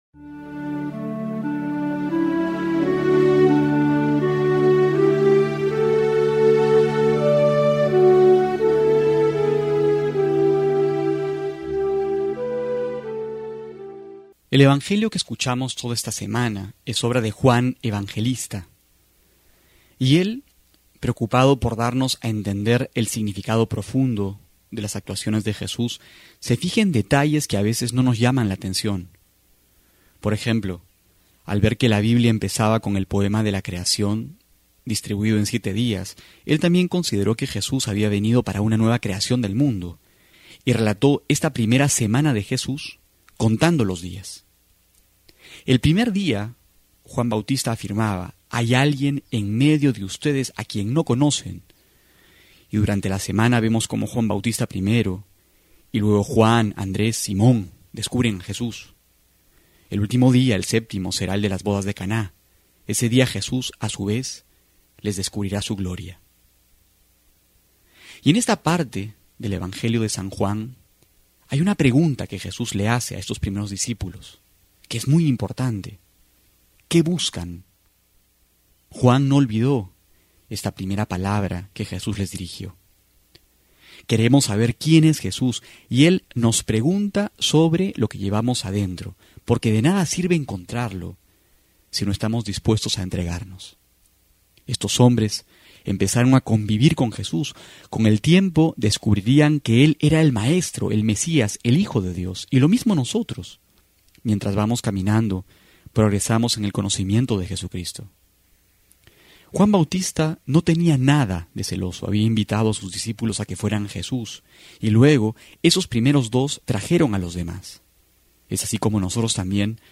Homilía para hoy:
enero04-13homilia.mp3